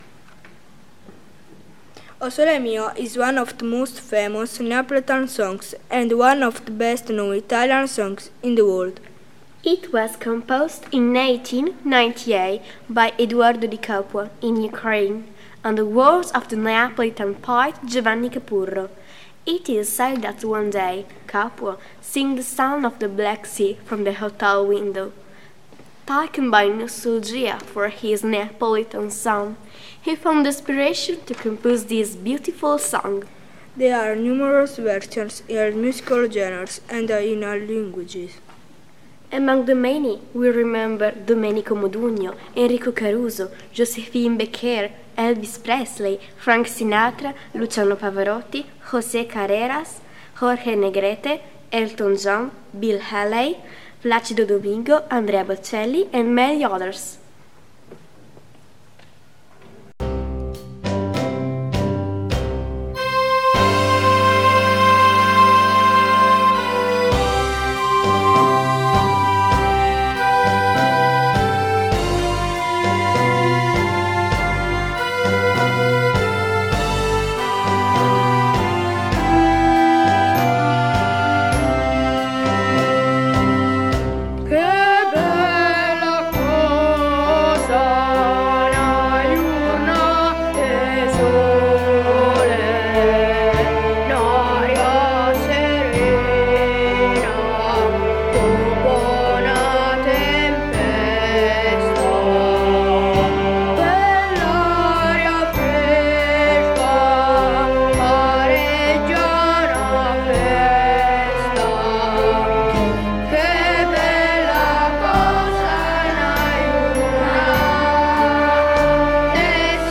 TRADITIONAL FOLK SONGS